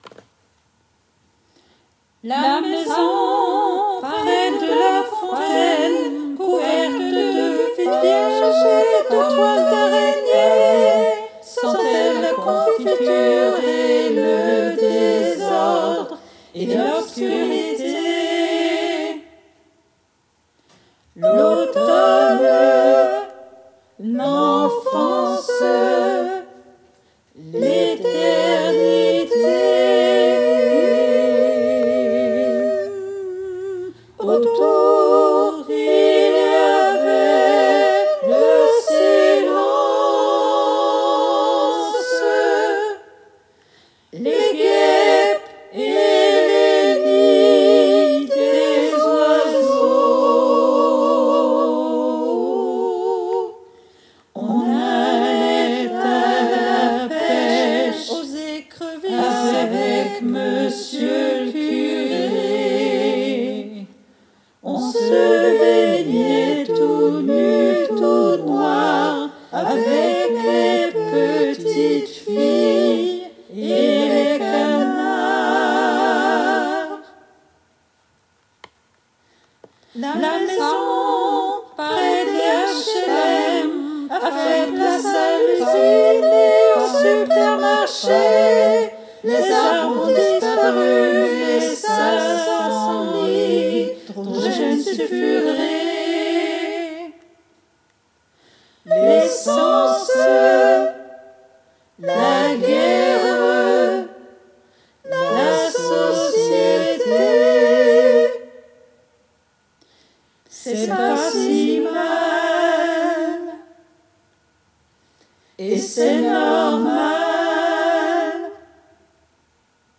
MP3 versions chantées
Tutti